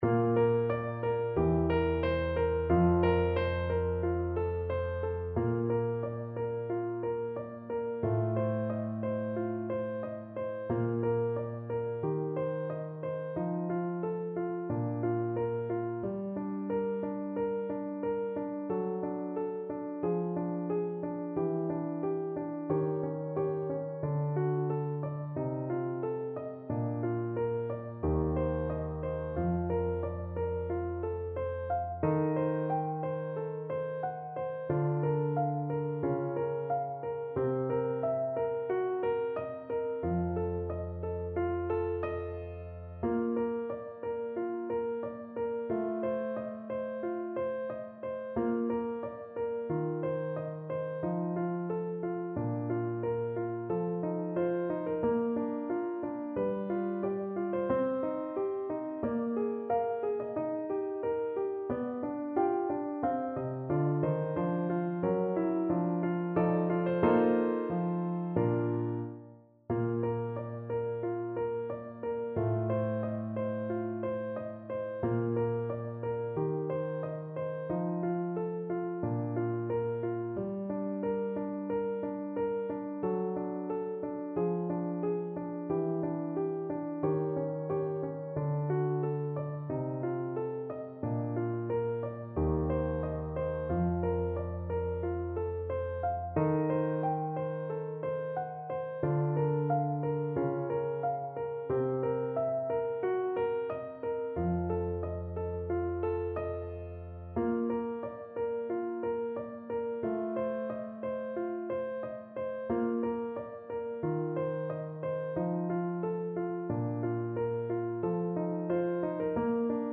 Flute version
Classical Flute